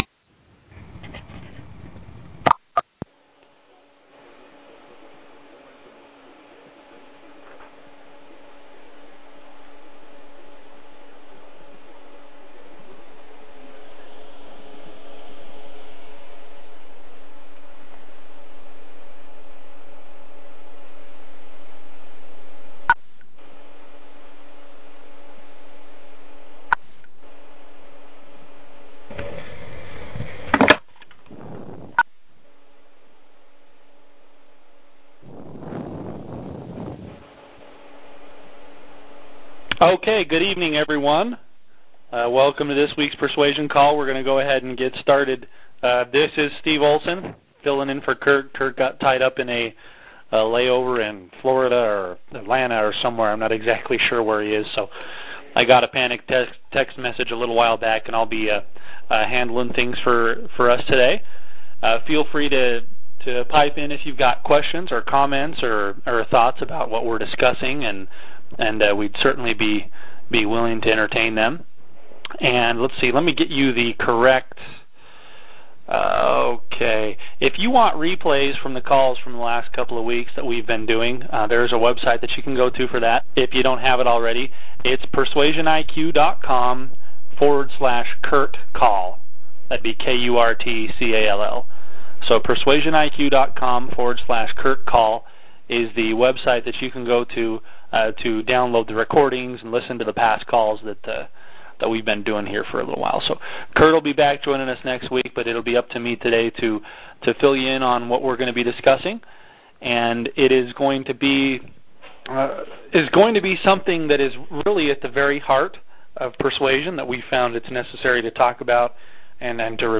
‹ Dissonance Intro Do People Trust You › Posted in Conference Calls